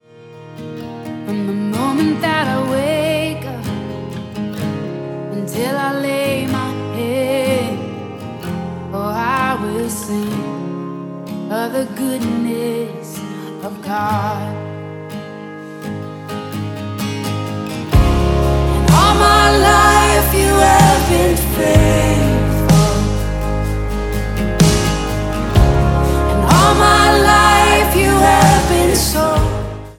confident, declarative songs recorded live